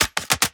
GUNMech_Insert Clip_02_SFRMS_SCIWPNS.wav